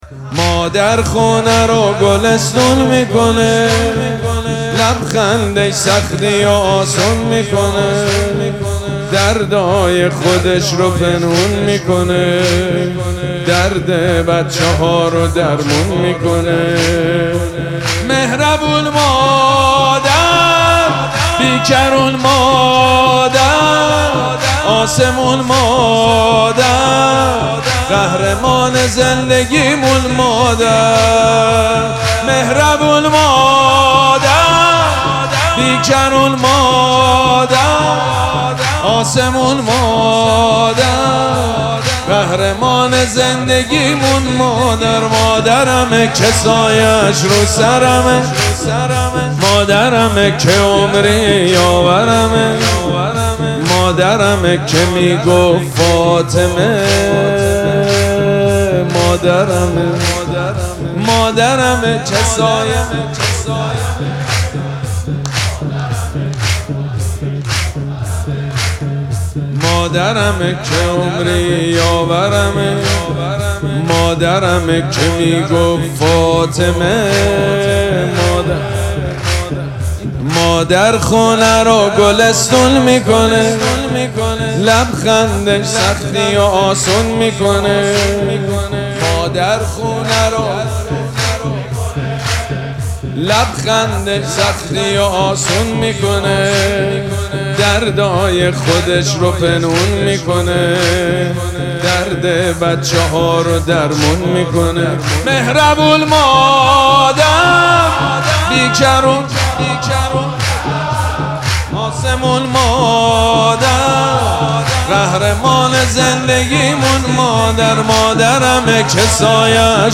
مراسم جشن ولادت حضرت زهرا سلام الله علیها
حسینیه ریحانه الحسین سلام الله علیها
سرود
حاج سید مجید بنی فاطمه